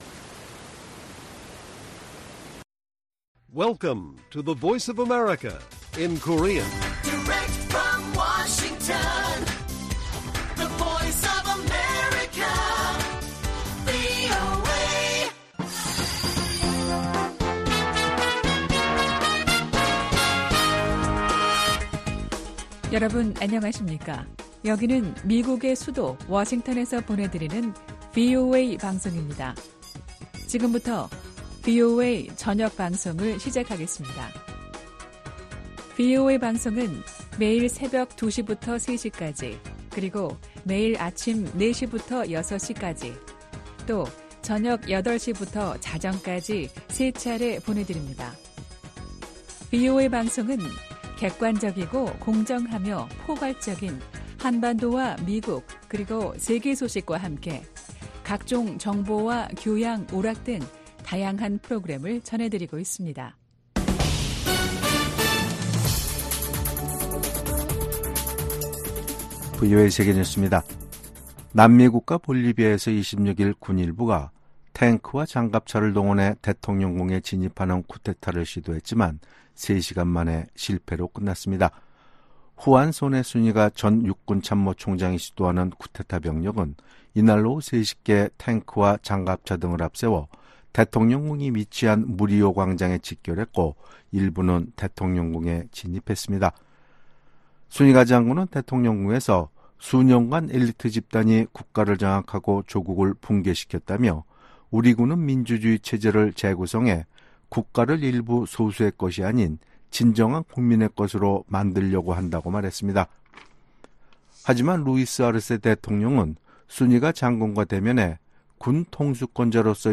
VOA 한국어 간판 뉴스 프로그램 '뉴스 투데이', 2024년 6월 27일 1부 방송입니다. 미국 정부는 러시아의 우크라이나 점령지로 북한 노동자가 파견될 가능성에 반대 입장을 분명히 했습니다.